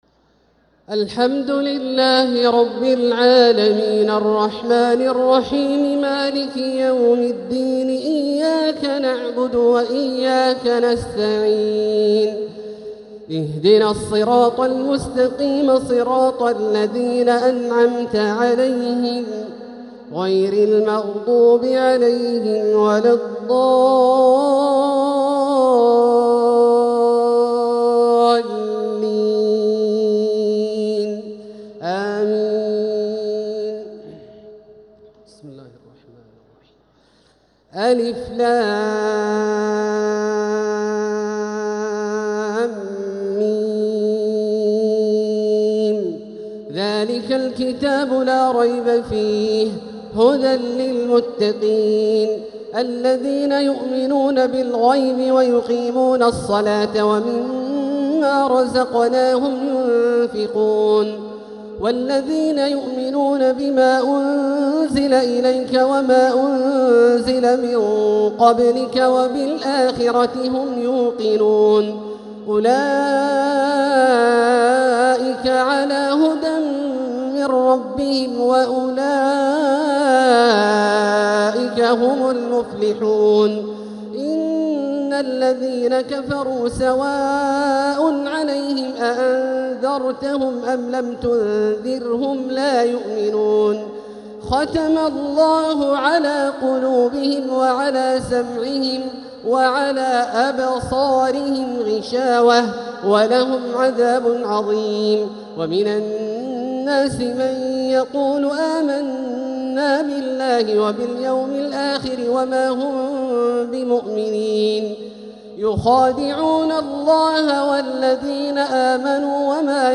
تراويح ليلة 1 رمضان 1446هـ من سورة البقرة (1-59) | Taraweeh 1st night Ramadan 1446H > تراويح الحرم المكي عام 1446 🕋 > التراويح - تلاوات الحرمين